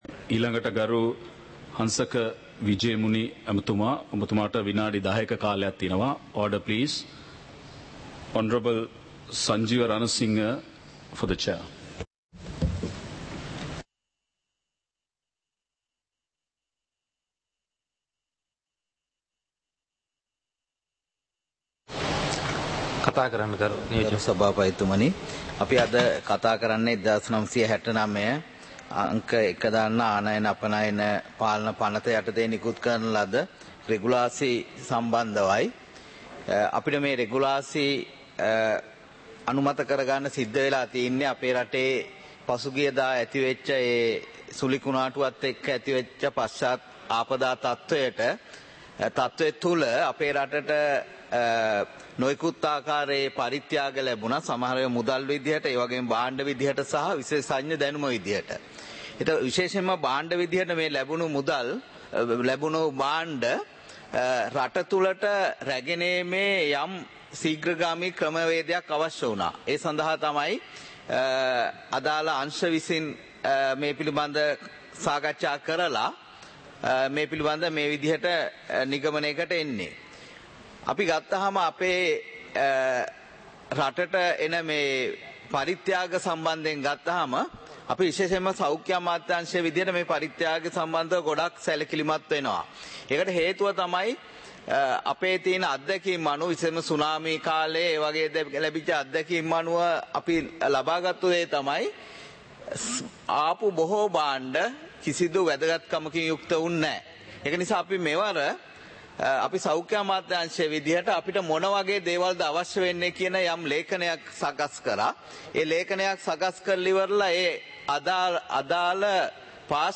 இலங்கை பாராளுமன்றம் - சபை நடவடிக்கைமுறை (2026-01-09)